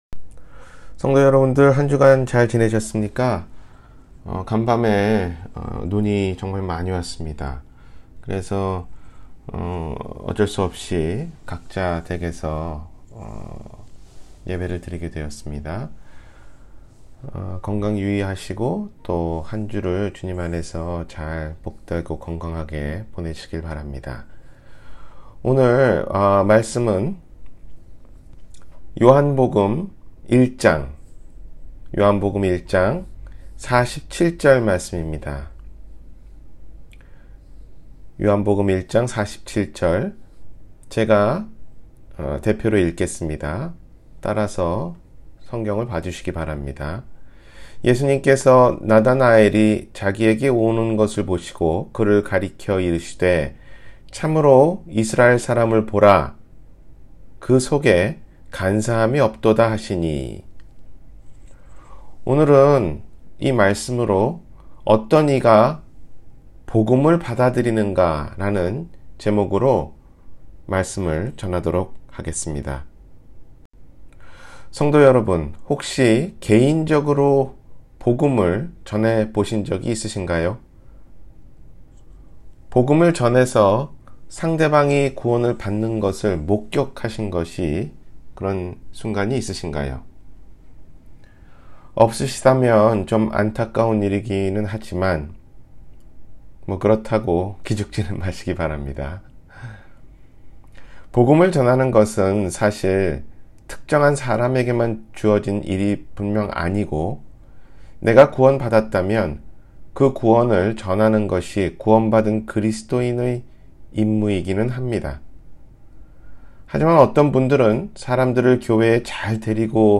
어떤이가 복음을 받아들이는가? – 주일설교